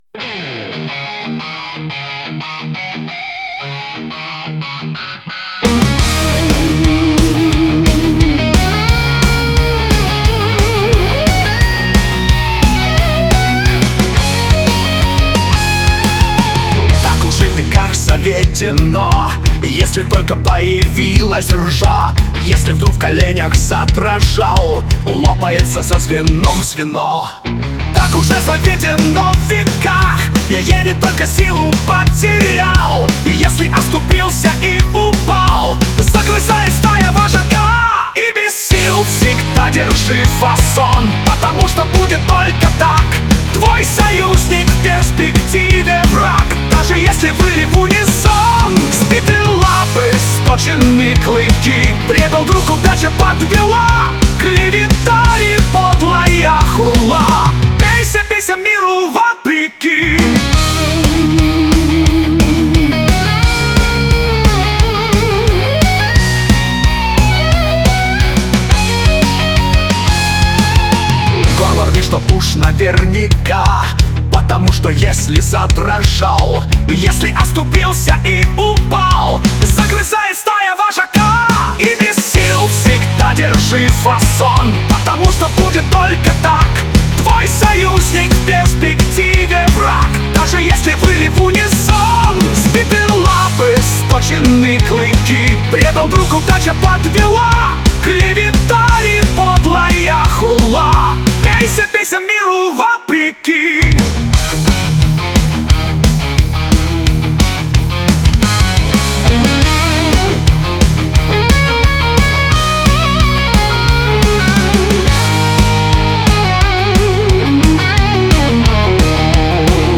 Песни с оркестровками [108]